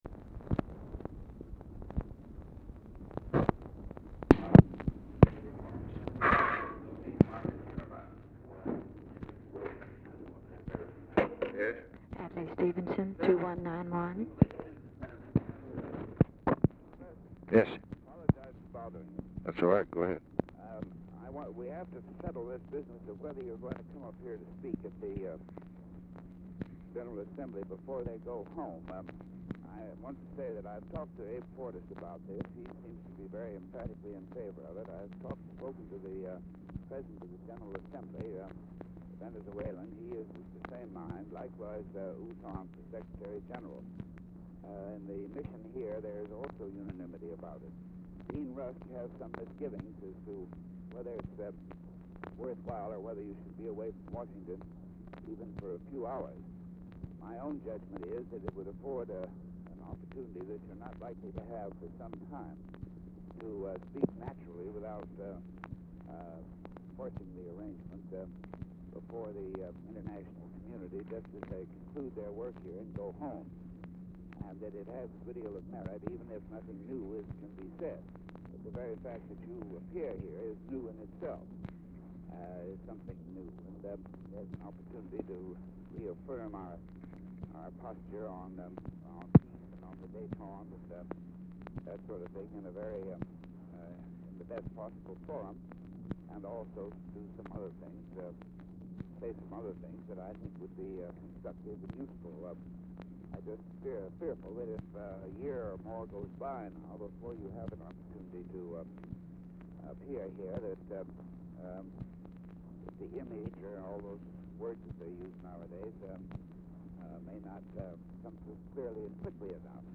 Oval Office or unknown location
Telephone conversation
Dictation belt